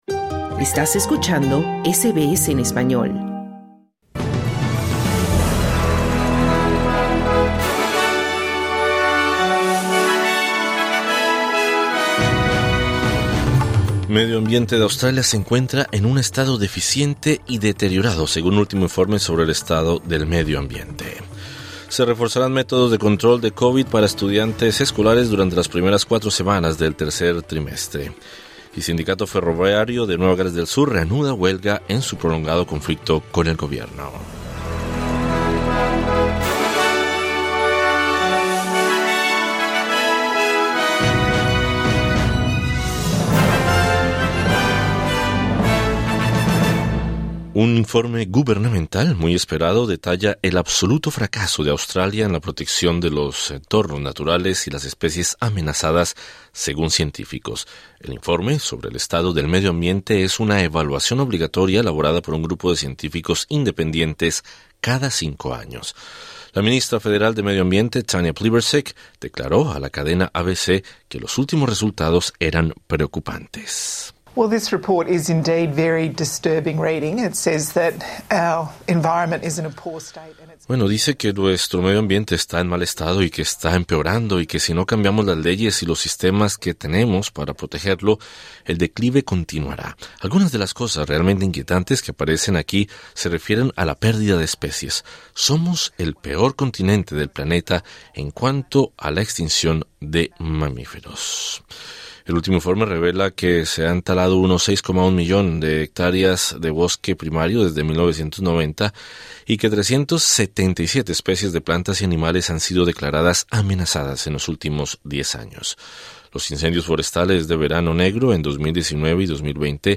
Noticias SBS Spanish | 19 de julio de 2022